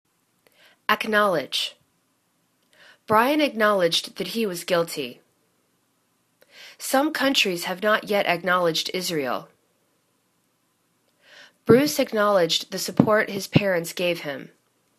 ac.knowl.edge     /ək'nolidg/    v